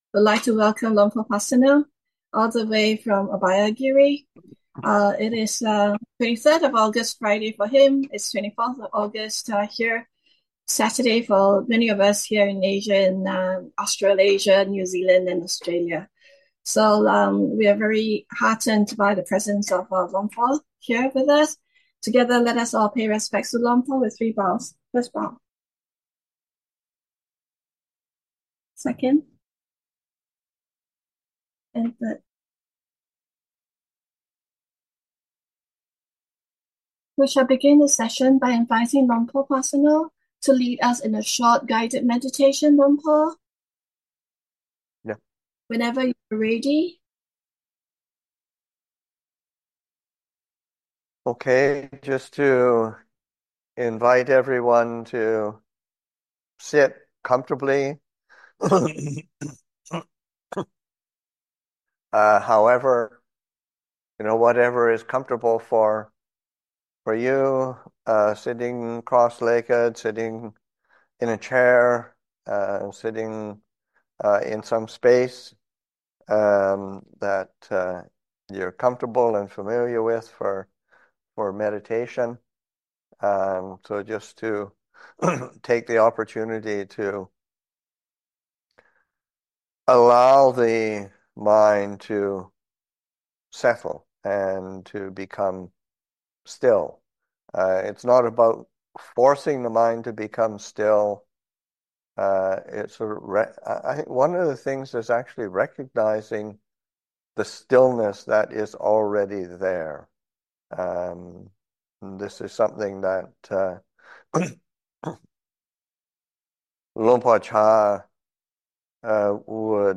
An online guided meditation, Dhamma talk, and question and answer session hosted by the BuddhaDhamma Foundation.